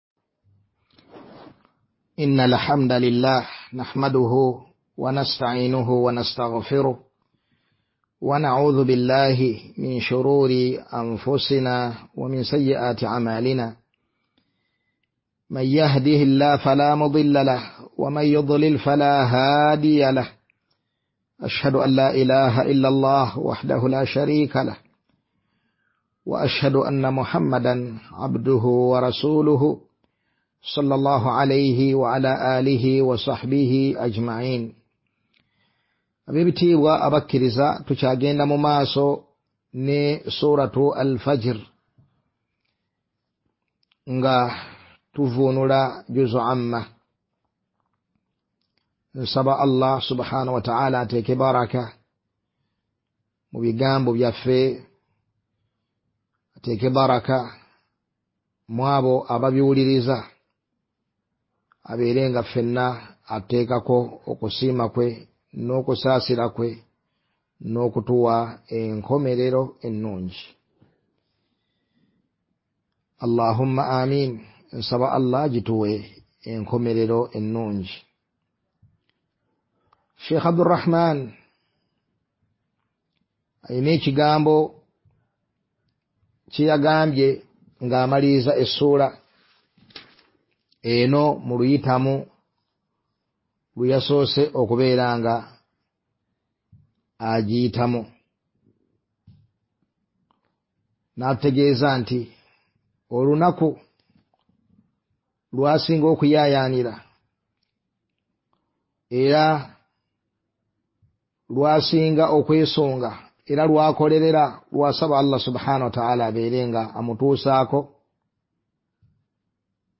Wuliliza Emisomo gya ba ma sheikh be uganda